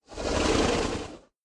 sounds / mob / horse / zombie / idle1.mp3